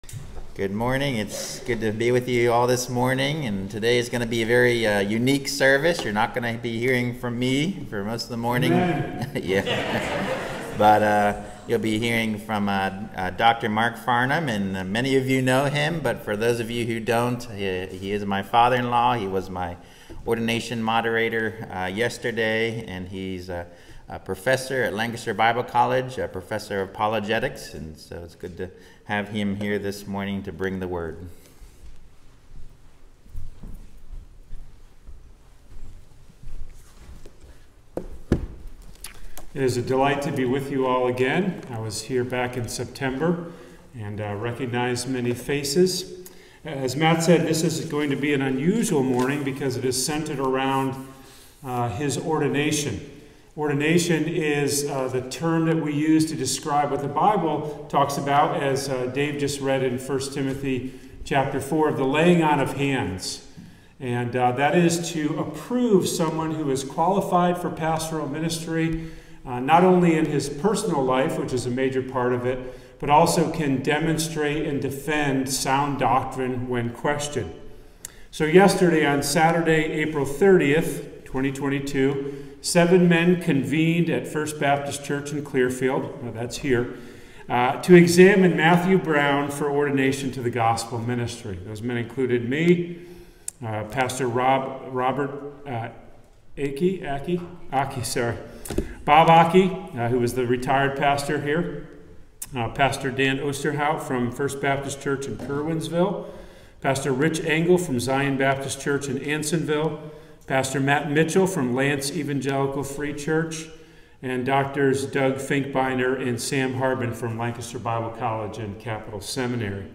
Ordination Service